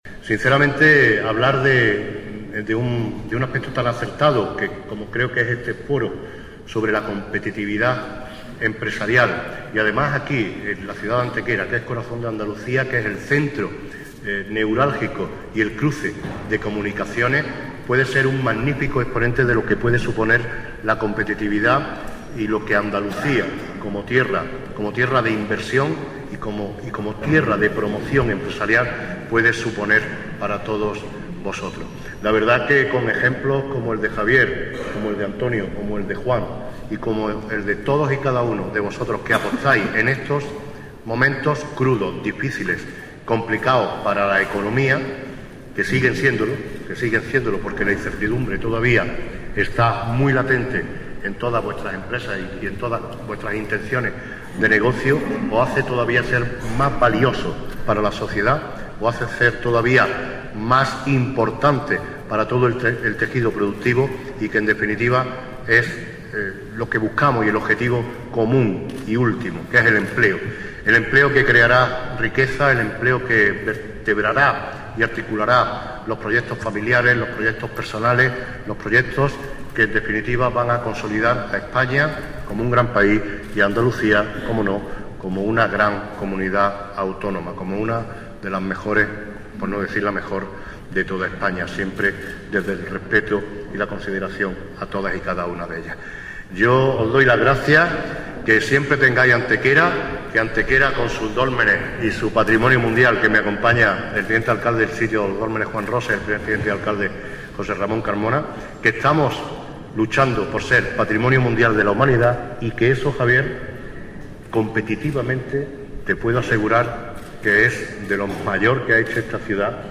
Generar Pdf jueves 17 de marzo de 2016 El Alcalde de Antequera inaugura el I Foro sobre Competitividad Empresarial en Andalucía que reune en nuestra ciudad a más de 500 empresarios Generar Pdf El alcalde de Antequera, Manolo Barón, era el encargado en la mañana de hoy jueves de inaugurar el I Foro sobre Competitividad Empresarial en Andalucía que, organizado por la Confederación de Empresarios de Andalucía en colaboración con el banco Popular y Telefónica ha reunido hoy en el Hotel La Magdalena de nuestra ciudad a más de 500 empresarios de diversos sectores de nuestra comunidad autónoma.
Cortes de voz M. Barón 950.67 kb Formato: mp3